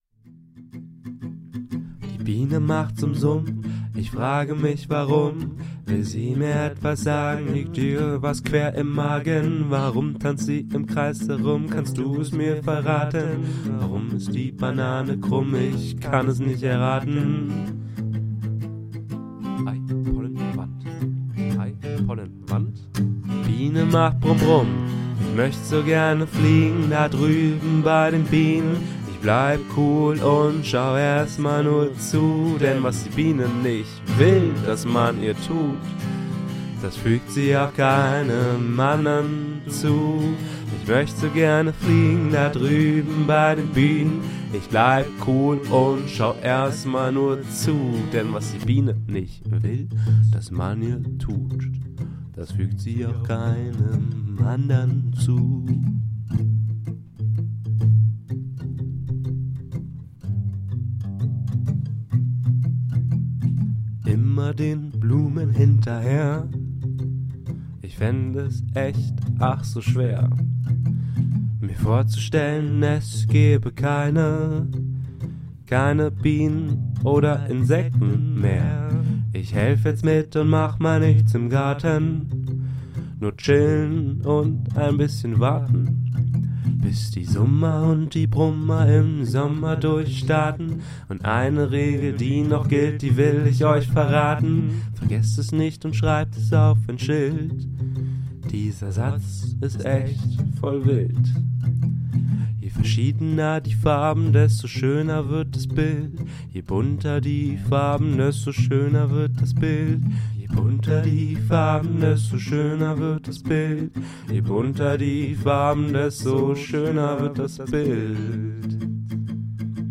Bienensong.mp3